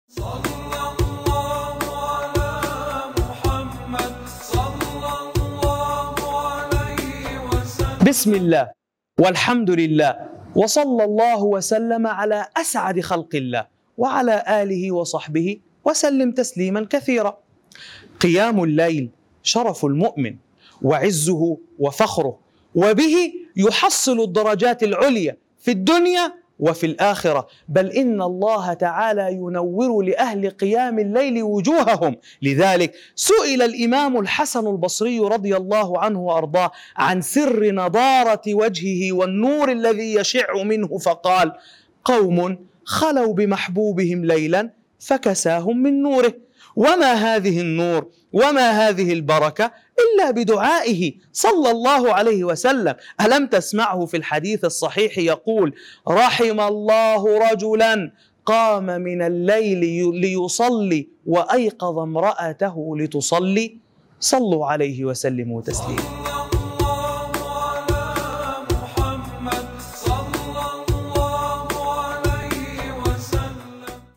نص موعظي يسلط الضوء على فضل قيام الليل وأثره العظيم على المؤمن في الدنيا والآخرة، من خلال ذكر ثمراته كنور الوجه والرفعة، مع الاستشهاد بقصة الإمام الحسن البصري وحديث نبوي شريف يحث على هذه العبادة الجليلة.